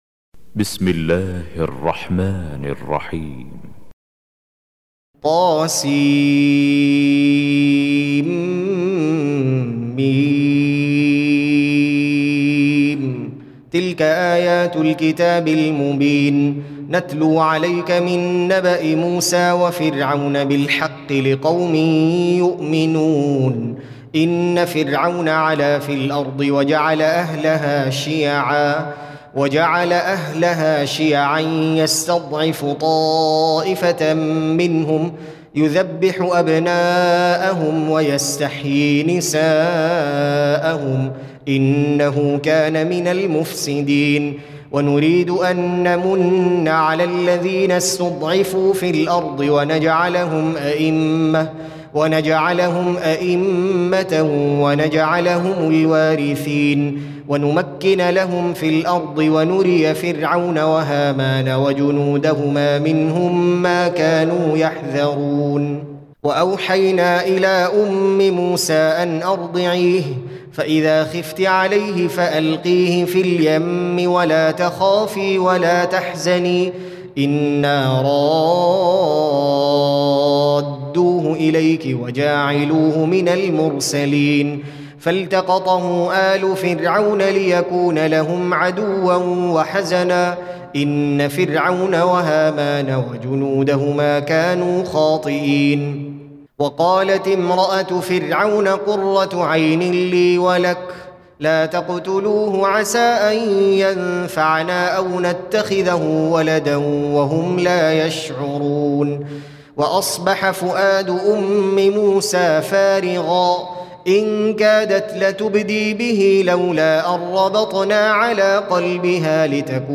28. Surah Al-Qasas سورة القصص Audio Quran Tarteel Recitation
Surah Sequence تتابع السورة Download Surah حمّل السورة Reciting Murattalah Audio for 28.